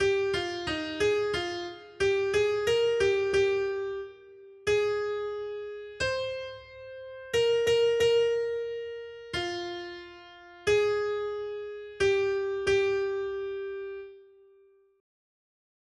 Noty Štítky, zpěvníky ol139.pdf responsoriální žalm Žaltář (Olejník) 139 Skrýt akordy R: Ukaž mi, Pane, cestu k životu. 1.